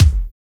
WHOMP KICK.wav